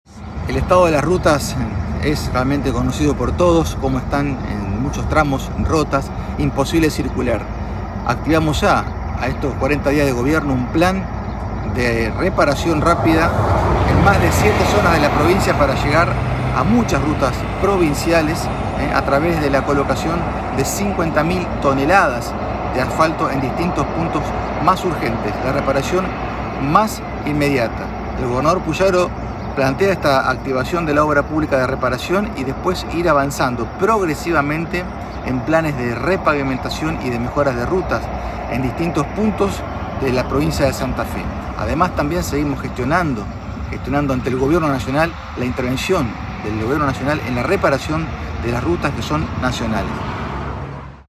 Audio del Ministro de Obras Públicas Lisandro Enrico